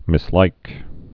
(mĭs-līk)